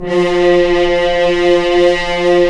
Index of /90_sSampleCDs/Roland L-CD702/VOL-2/BRS_F.Horns FX+/BRS_FHns Mutes
BRS F.HRNS00.wav